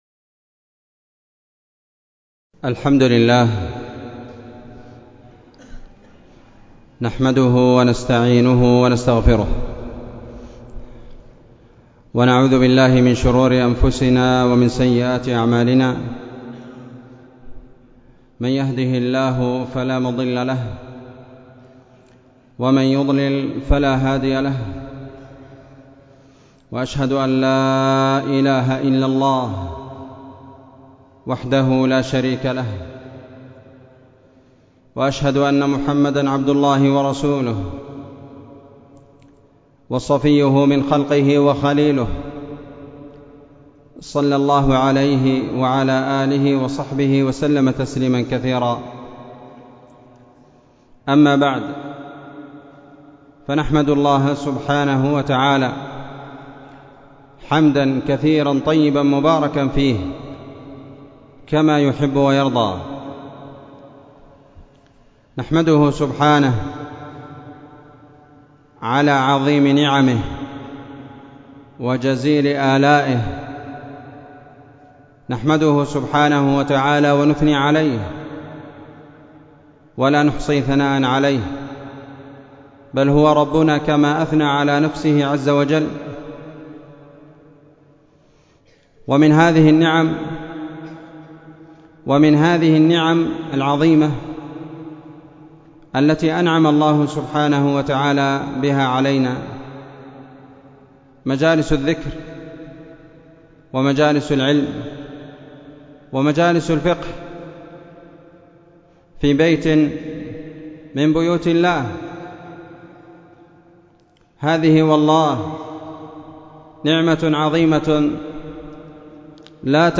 محاضرة قيمة وموعظة بليغة بعنوان:
في مسجد الجمالي- تعز